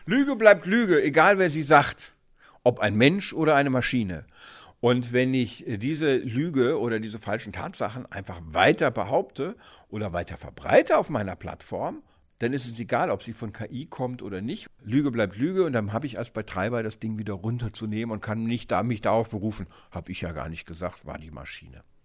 O-Ton: „Halluzinierende“ KI – Betreiber haftet für unwahre Fakten-Checks durch Chatbots – Vorabs Medienproduktion